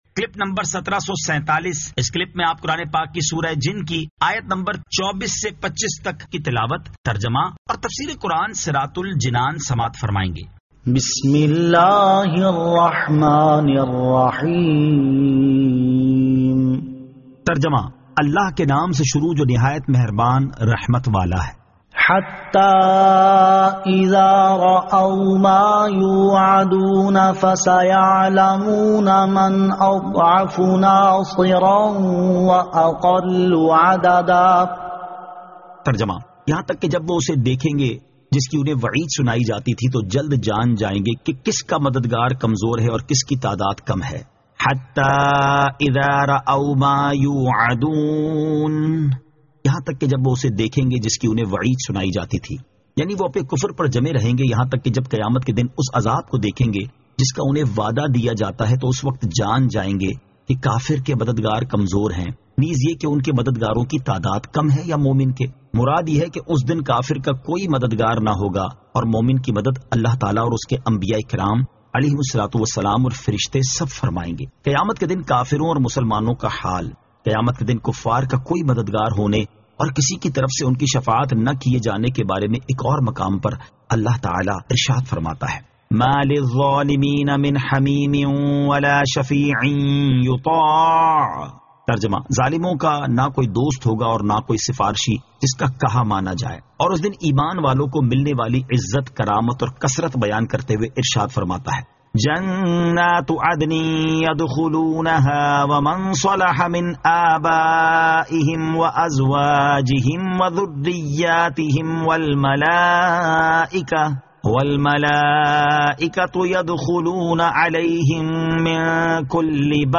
Surah Al-Jinn 24 To 25 Tilawat , Tarjama , Tafseer